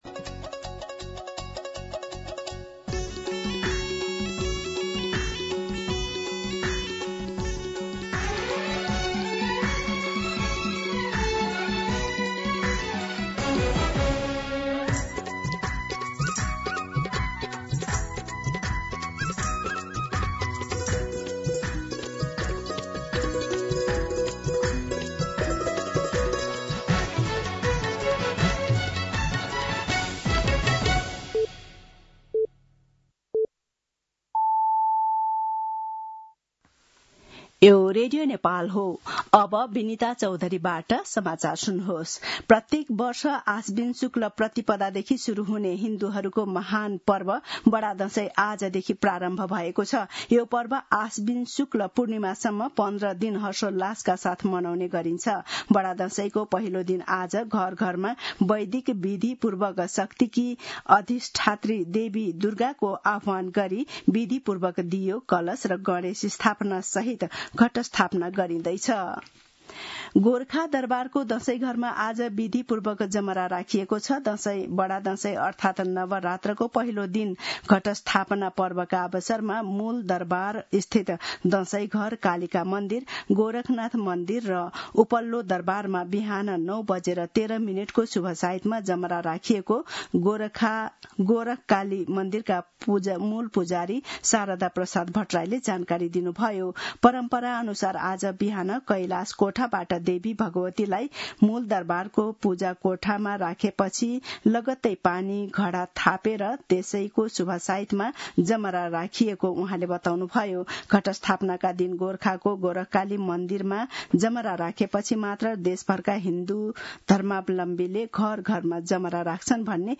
दिउँसो १ बजेको नेपाली समाचार : ६ असोज , २०८२
1-pm-Nepali-News-3.mp3